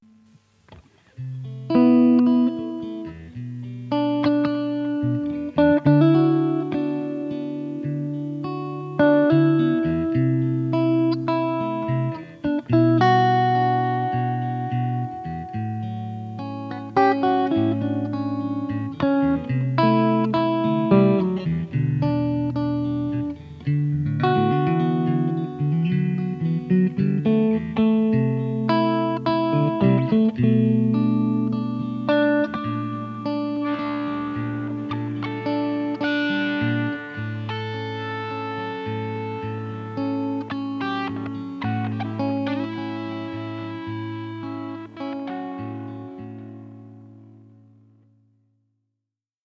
Das heutige Mitbringsel kleiner Fender-Exkursionen in die Höhlenwelt der Sound-Escapes brachte drei kleine Stücke ans Tageslicht, sie heissen: